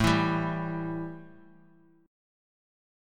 A 5th 7th